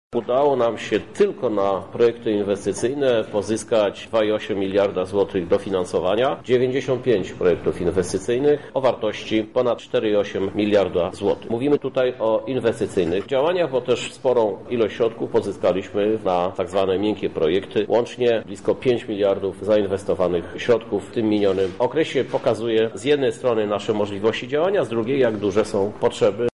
O tym, ile funduszy udało się pozyskać Lublinowi na projekty inwestycyjne, mówi prezydent miasta Krzysztof Żuk: